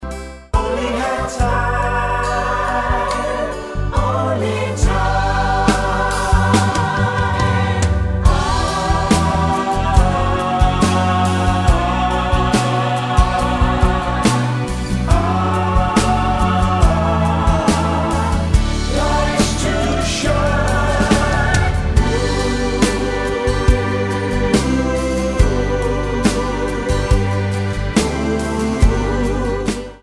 Tonart:G mit Chor